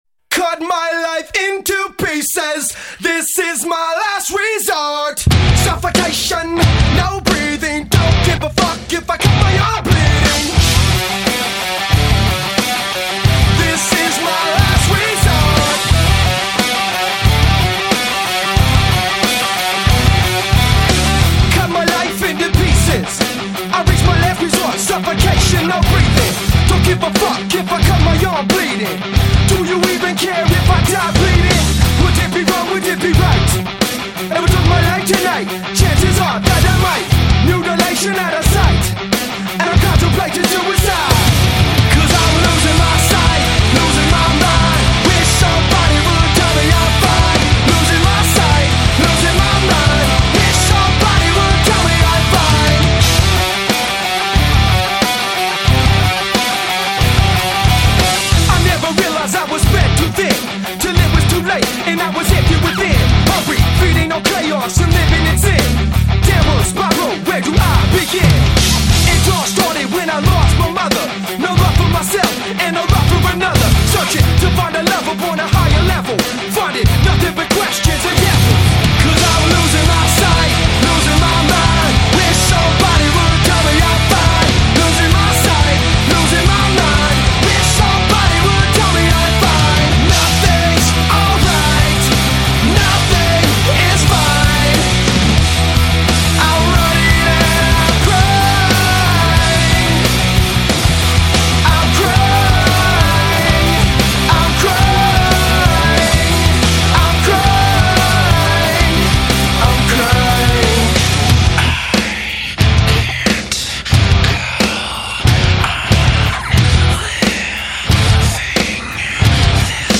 Жанр: numetal